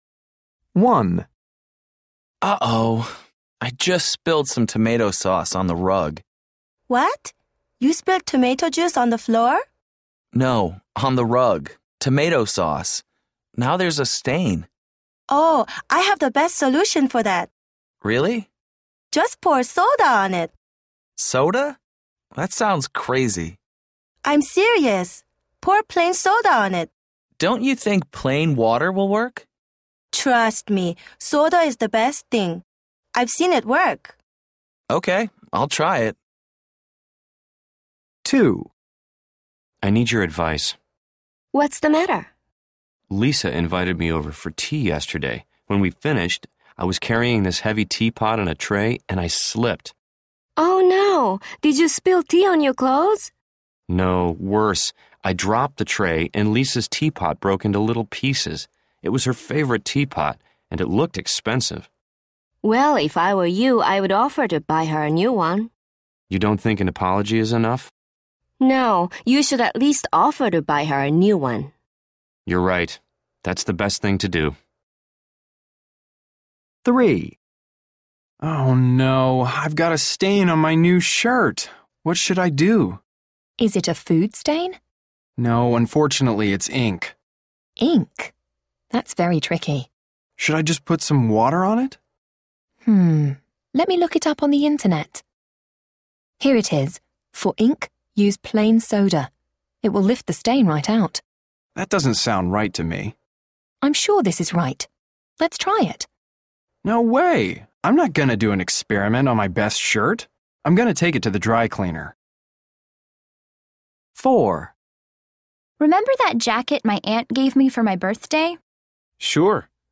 A. People are discussing mishaps.